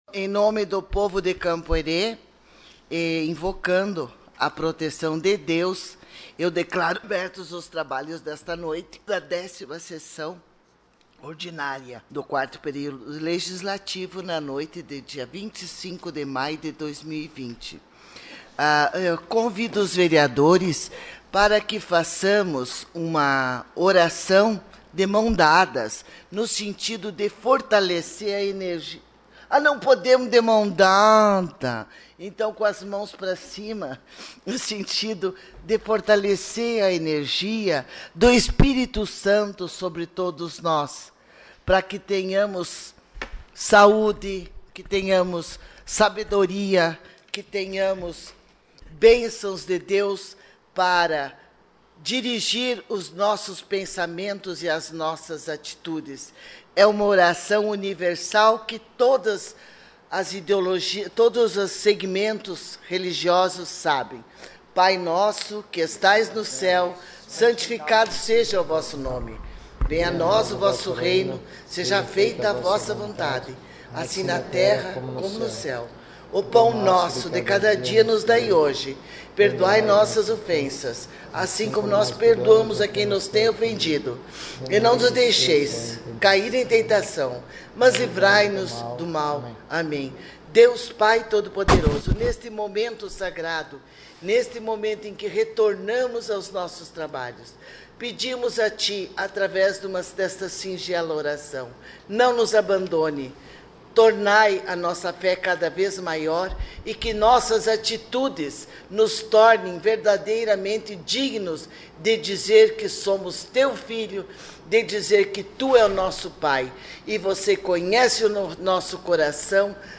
Sessão Ordinária 25 de maio de 2020